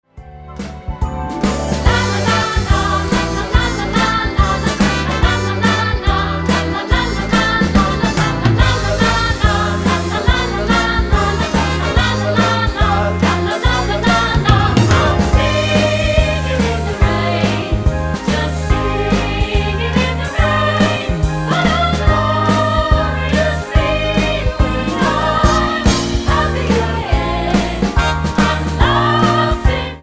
Tonart:G-Ab mit Chor